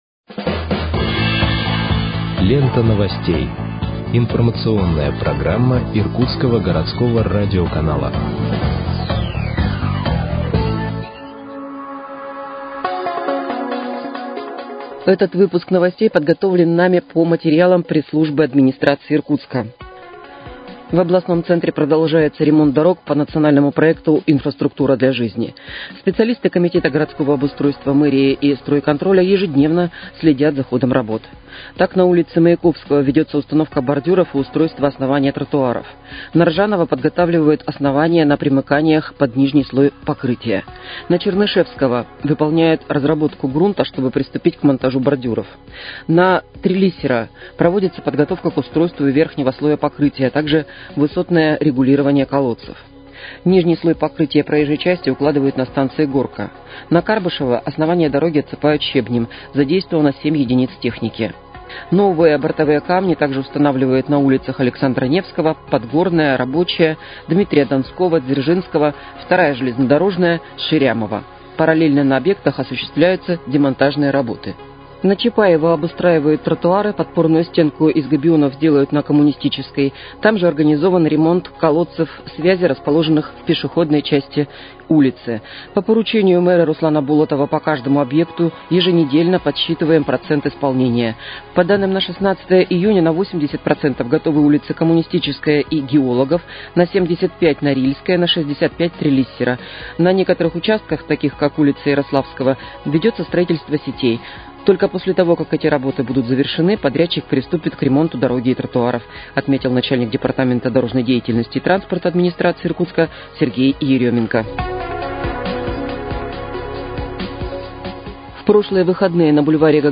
Выпуск новостей в подкастах газеты «Иркутск» от 20.06.2025 № 2